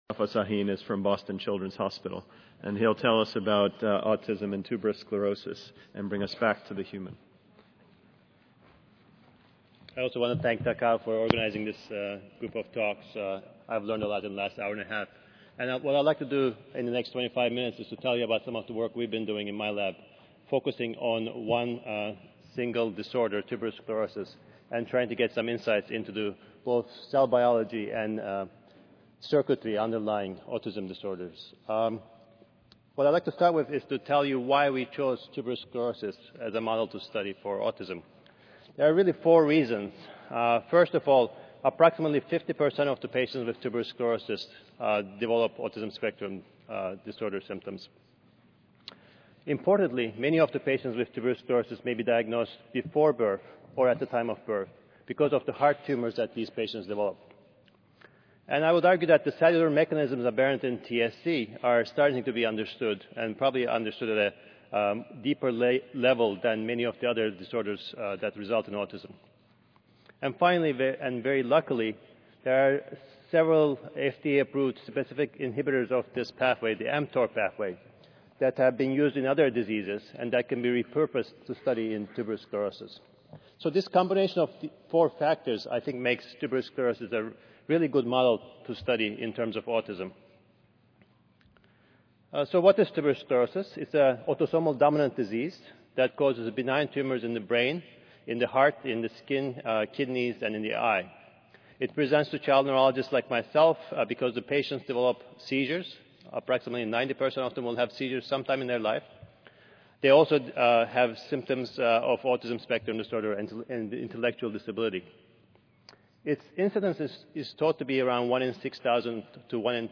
2013 International Meeting for Autism Research: Autism in Tuberous Sclerosis: The Case for the Cerebellum